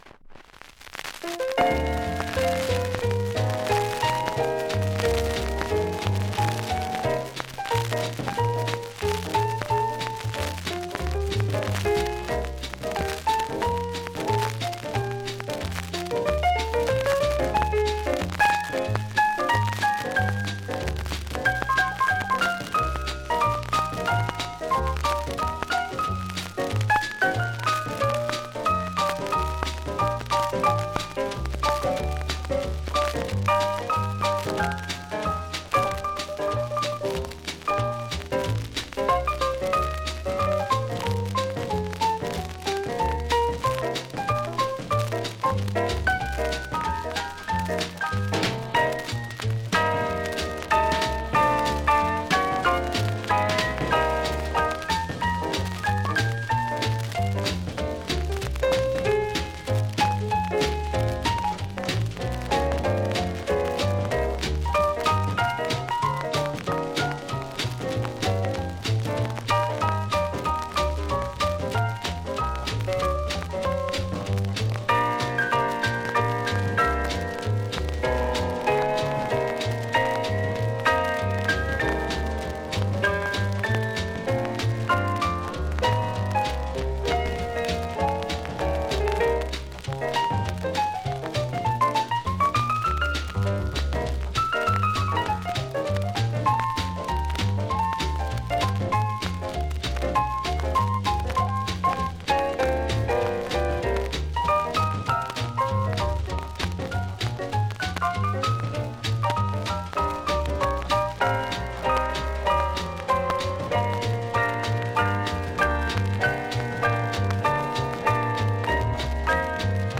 ◆盤質Ａ面/G ◆盤質Ｂ面/Gサーフェスノイズ多め